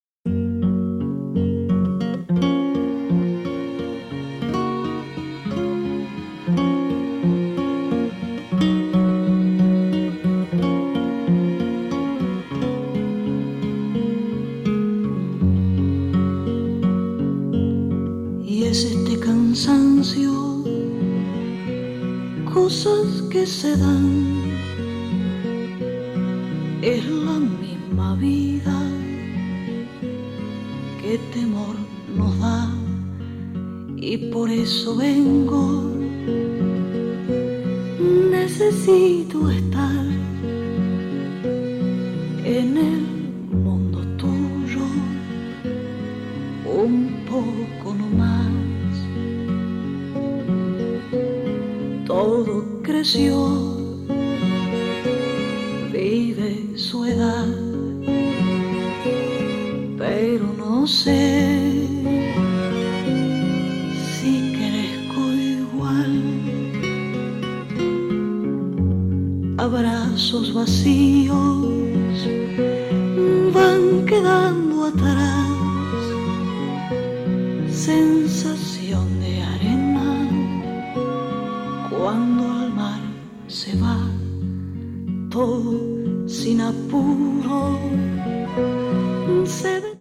ファドとボサノヴァからの影響を受けつつ、それをアシッド・フォーク風味にまとめ上げた傑作！